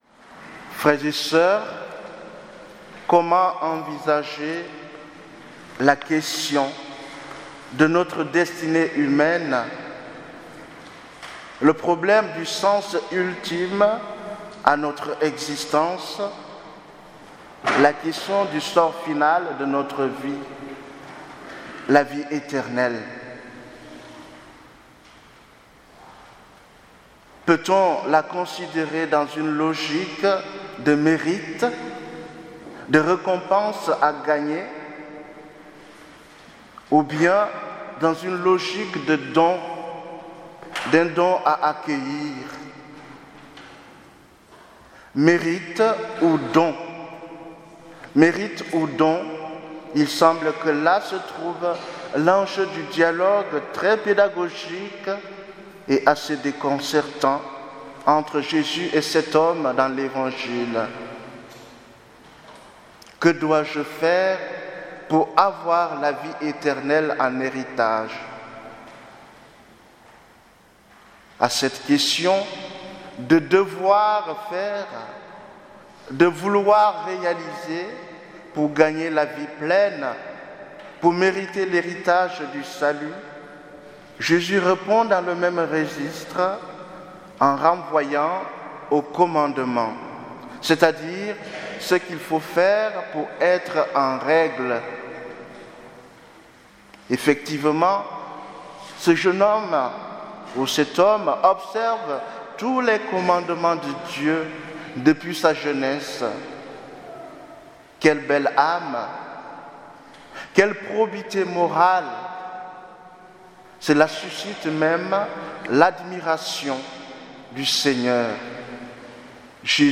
Homélie du 28ème dimanche du Temps Ordinaire